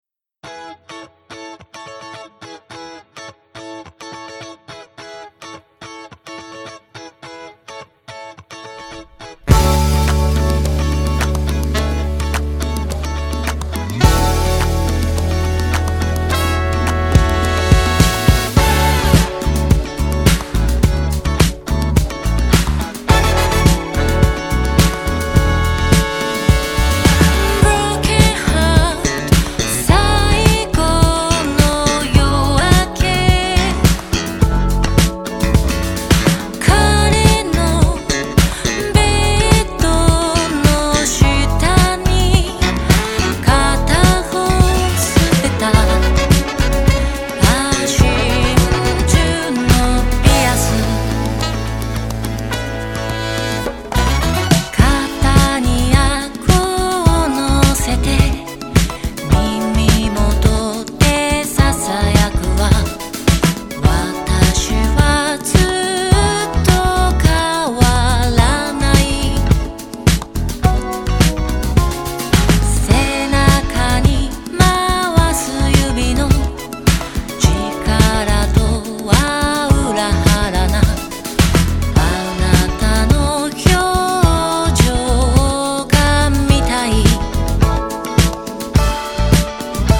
CITY POP / AOR